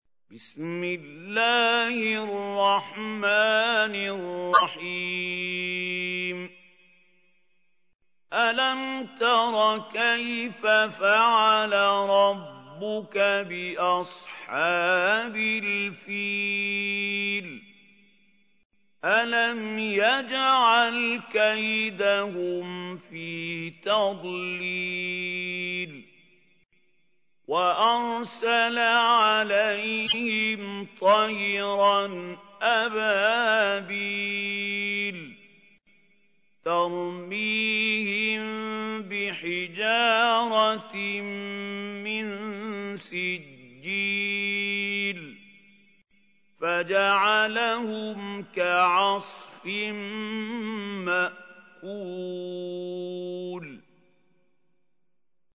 تحميل : 105. سورة الفيل / القارئ محمود خليل الحصري / القرآن الكريم / موقع يا حسين